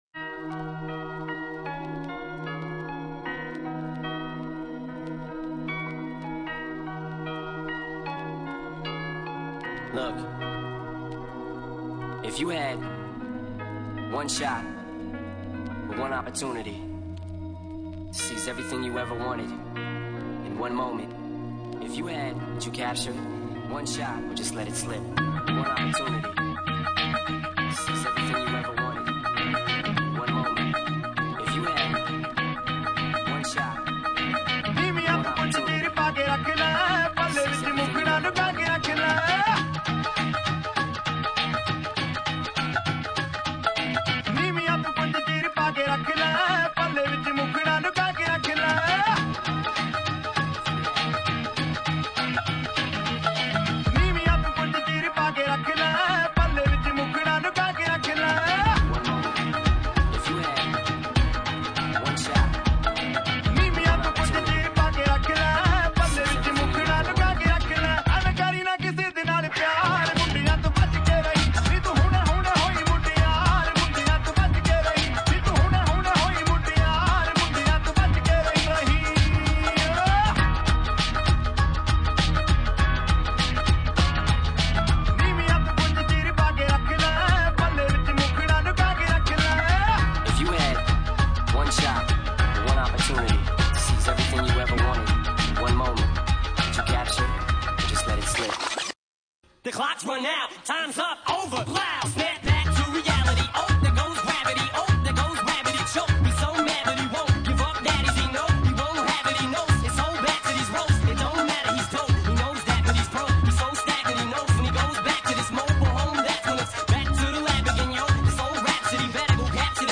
without bass